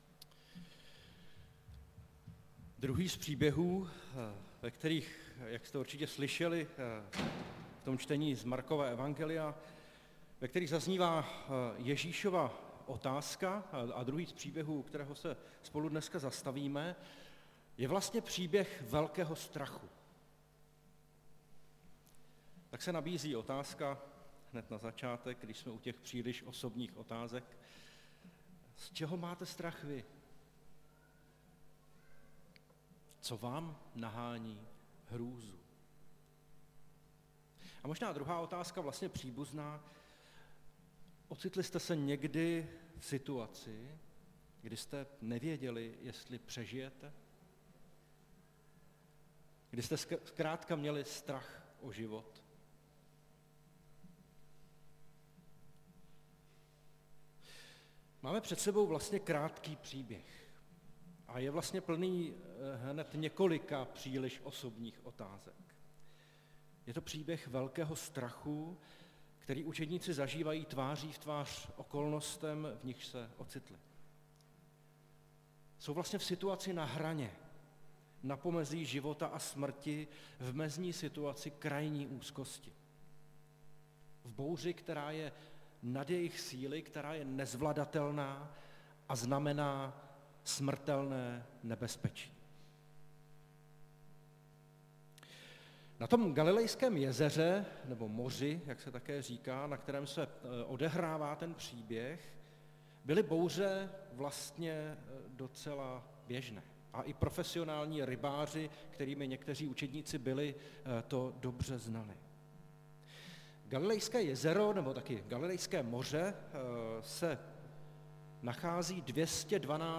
Nedělní kázání – 5.2.2023 Ještě nemáte víru?